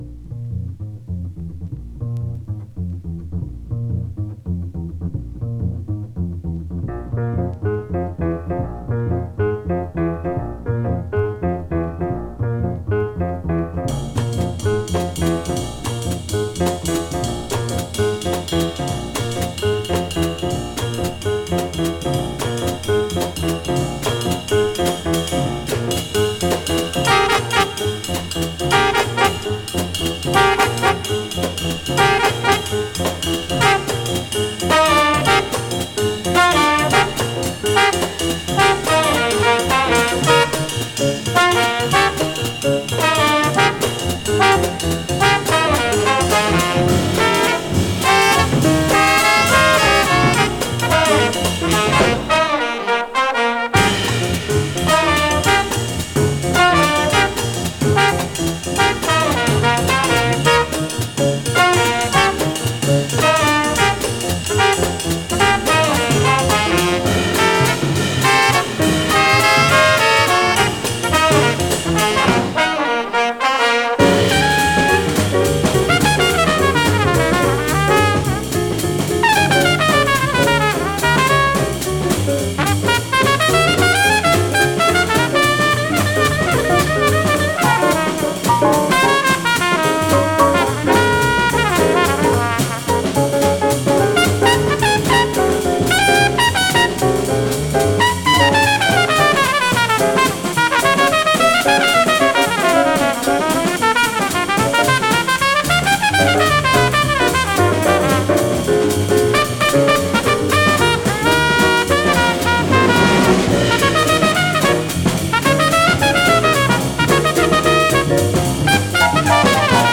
trombone
tenor sax
trumpet
piano
bass
drums
The hardest of hard bop